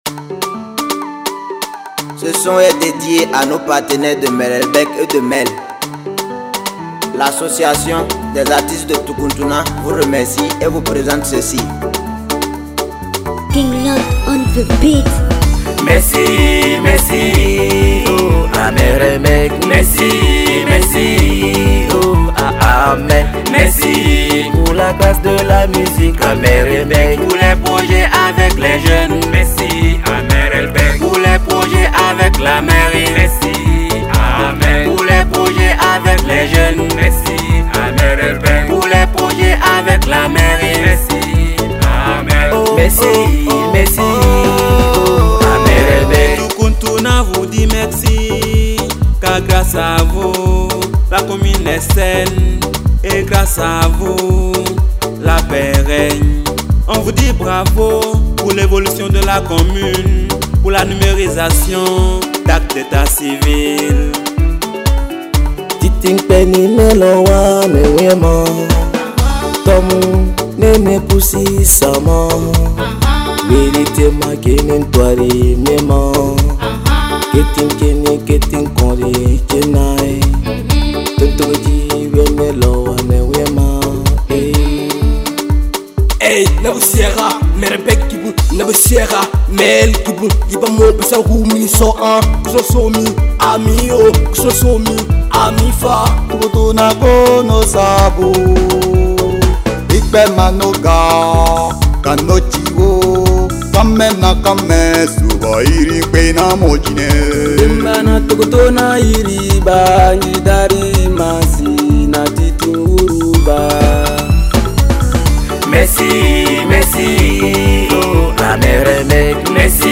In het jeugdhuis van Toucountouna vond in september een muziekstage plaats voor een een vijftien jongeren.
De jongeren schreven en componeerden zelf een lied. De verschillende stemmen werden ingezongen, verschillende muziekinstrumenten bespeeld.